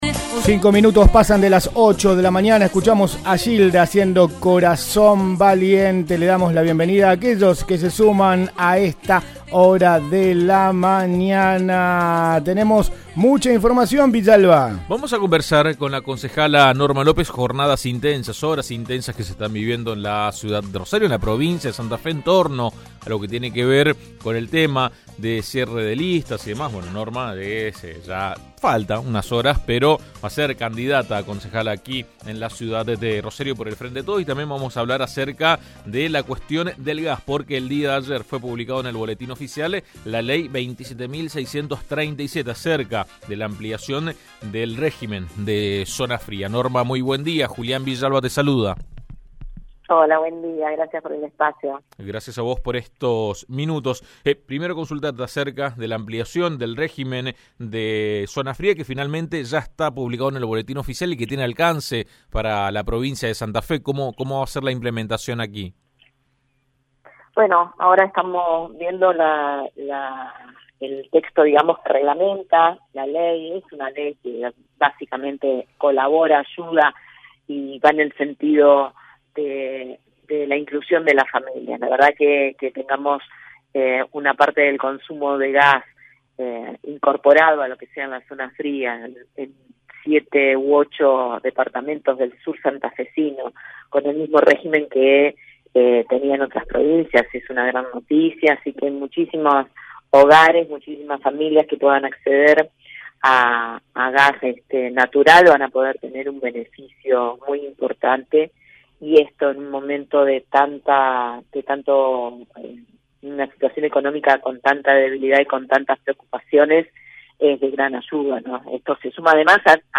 La concejala Norma López competirá como precandidata a renovar su banca por el Frente de Todos y dialogó con AM 1330.